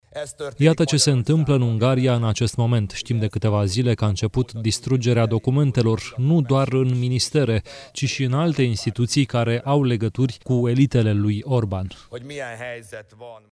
În conferința de presă ținută după victorie, liderul partidului TISZA, Péter Magyar, care ar urma să preia funcția de premier, l-a acuzat pe ministrul de externe din cabinetul Orbán că ar fi distrus documente confidențiale care ar atesta legături strânse cu Rusia.